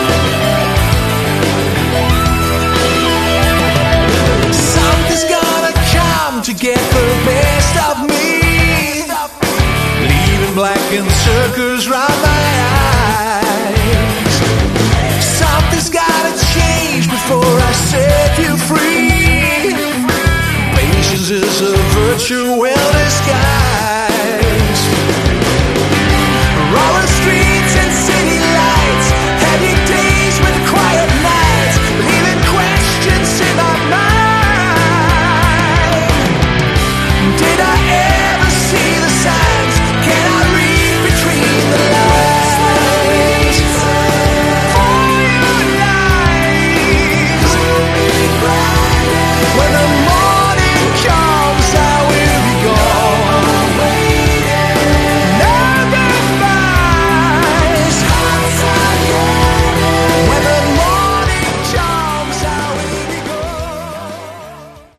Category: Melodic Hard Rock / Metal